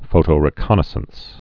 (fōtō-rĭ-kŏnə-səns, -zəns)